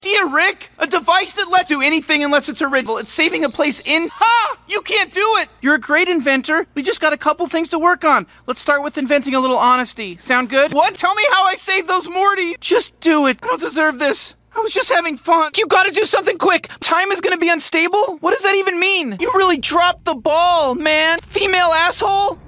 Neurotic Teenage English.MP3